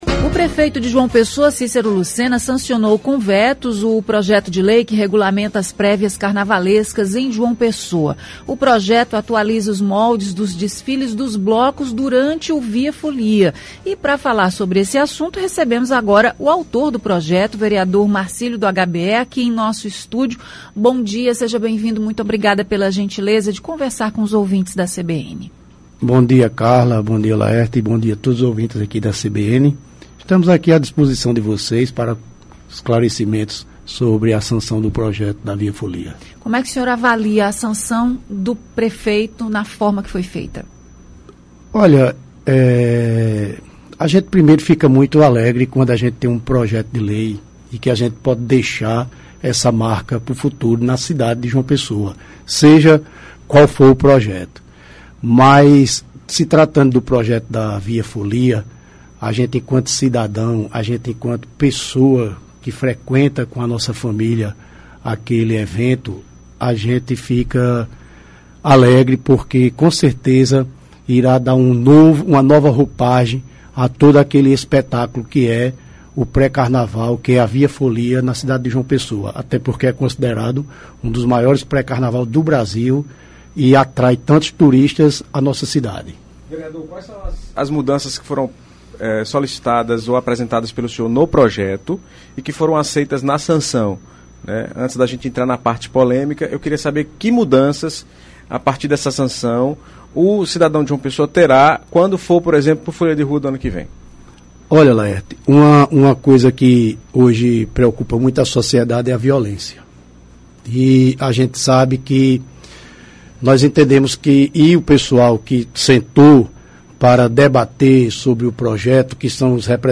Entrevista: Vereador Marcílio do HBE fala sobre a lei que disciplina a Via Folia – CBN Paraíba
O vereador de João Pessoa Marcílio do HBE foi o entrevistado desta segunda-feira (23). Ele fala sobre a lei que disciplina a Via Folia.